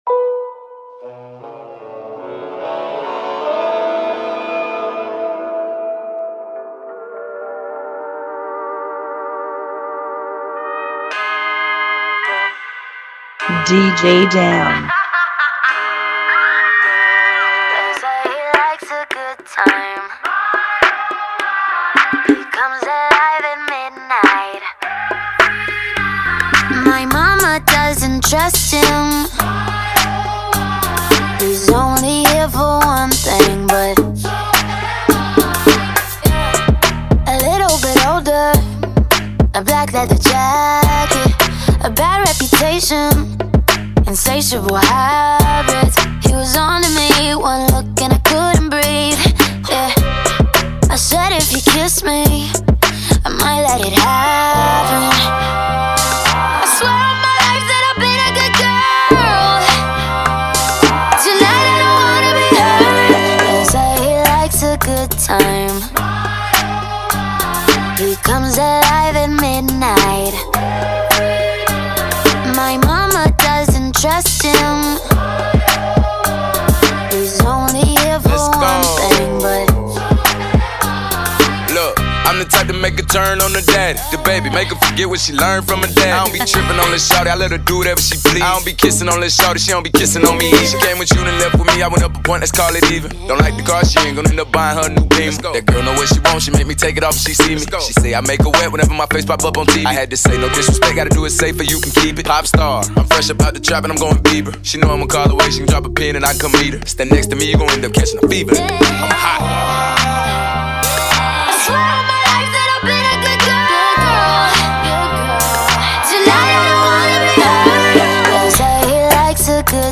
105 BPM
Genre: Bachata Remix